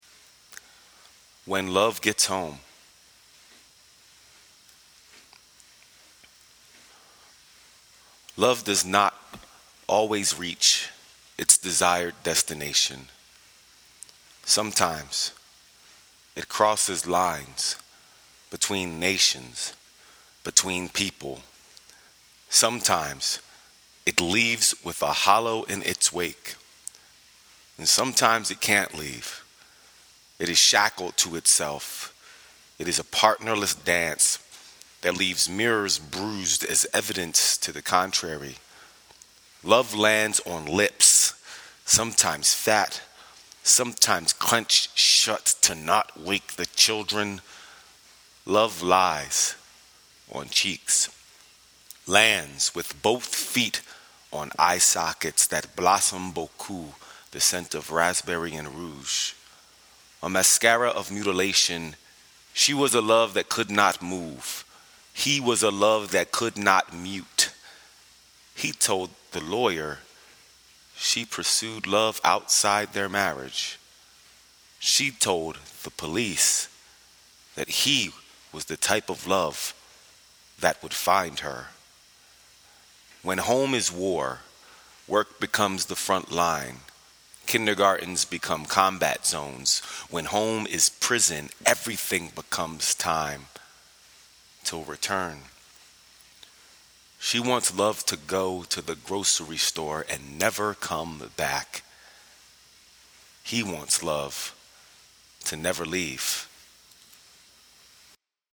read When Love Gets Home